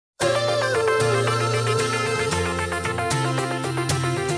• Качество: 224, Stereo
гитара
мужской голос
громкие
спокойные
Pop Rock